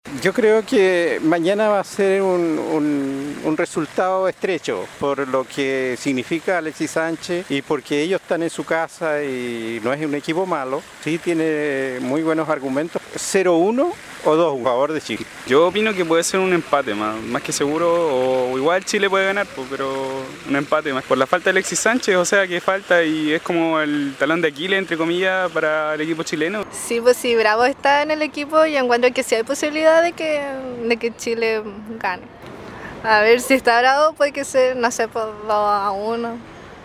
Osorninos pronostican resultado de Chile vs Colombia - RadioSago